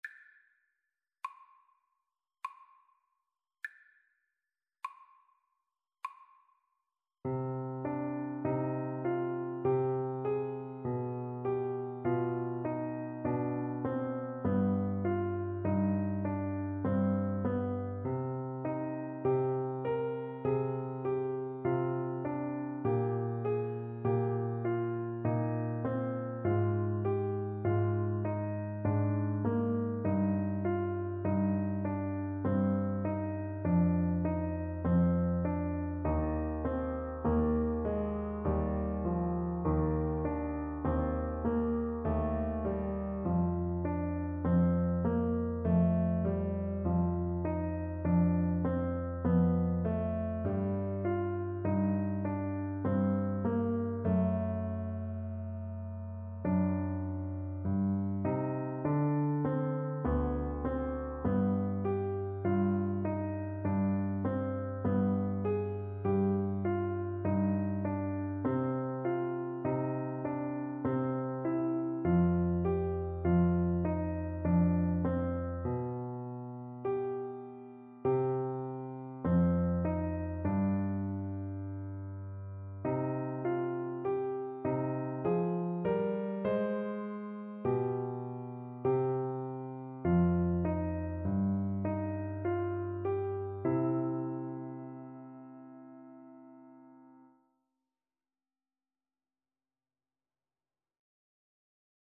3/4 (View more 3/4 Music)
Largo
Classical (View more Classical Clarinet Music)